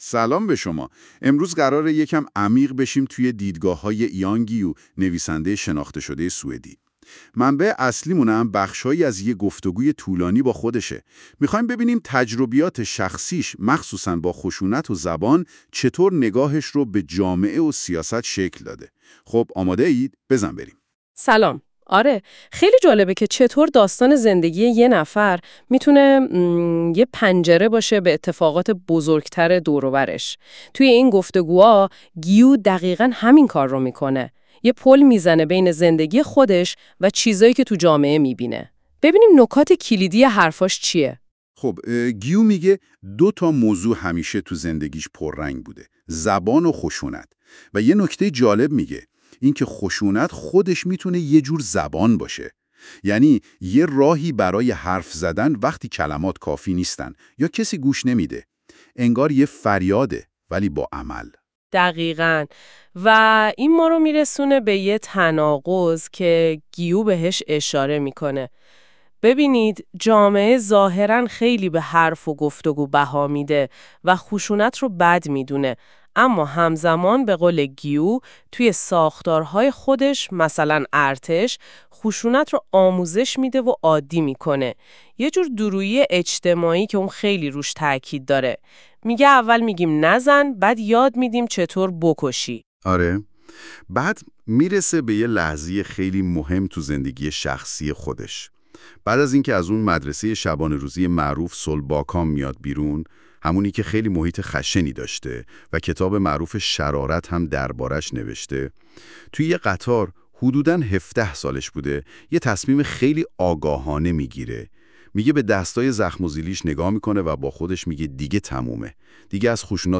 یان گیو، یکی از پرکارترین و سرشناس‌ترین نویسندگان سوئدی با بیش از ۵۰ عنوان کتاب و پیشینه‌ای طولانی در روزنامه‌نگاری که از اوایل دهه ۷۰ میلادی آغاز شده است، در گفتگویی روشنگرانه، دیدگاه‌های تند و در عین حال عمیقی را درباره مسائل کلیدی جامعه معاصر، از جمله خشونت، جنسیت، سیاست‌های هویتی، و سیستم آموزشی بیان می‌کند.